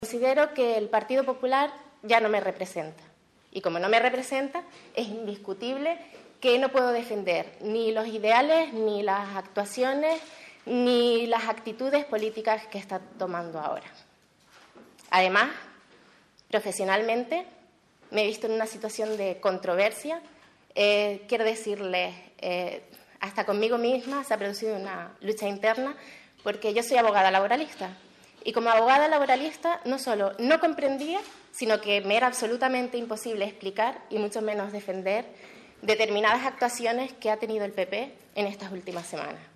Así lo ha puesto de manifiesto la propia edil popular, que en rueda de prensa ofrecida a las 11 horas en el Archivo Municipal de Arrecife ha dejado claro que, a pesar de que la propia presidenta del PP en Lanzarote, Ástrid Pérez, le ha rogado que entregue su acta de concejal, no piensa hacerlo.